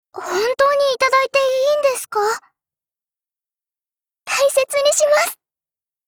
贡献 ） 分类:碧蓝航线:雅努斯语音 您不可以覆盖此文件。